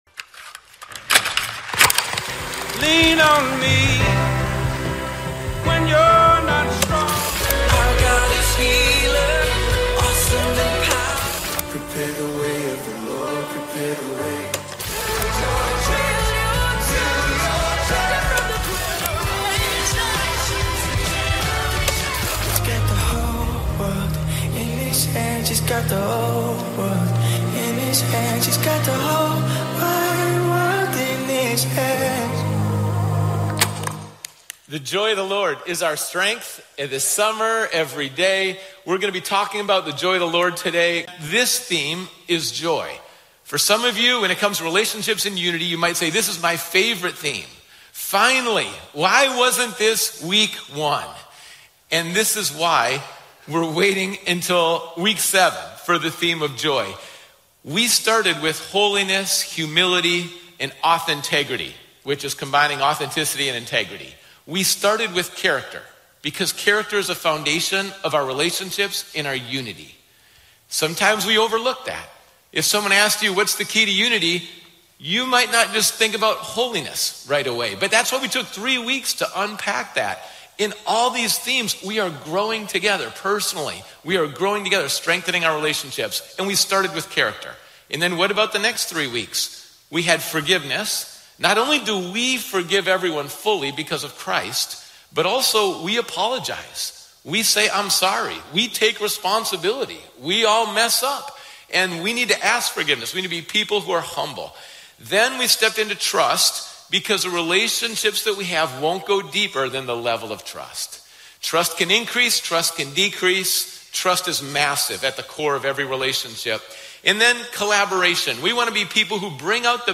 The Bonfire Talk Show